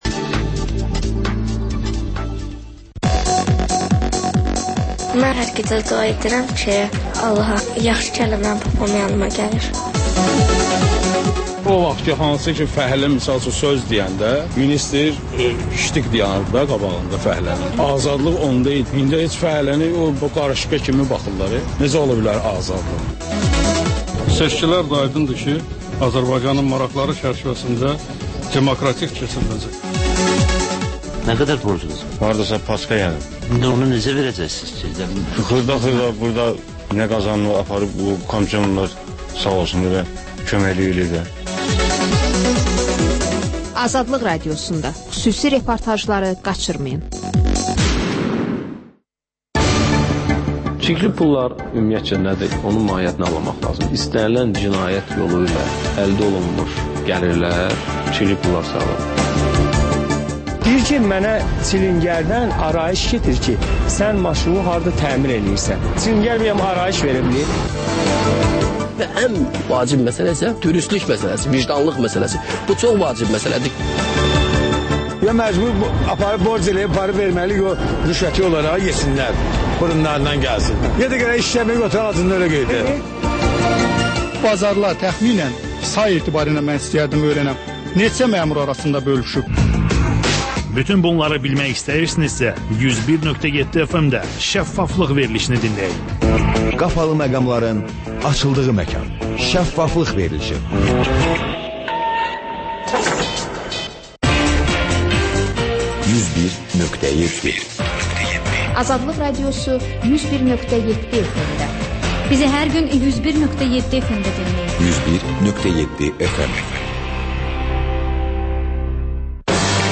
Ölkənin tanınmış simaları ilə söhbət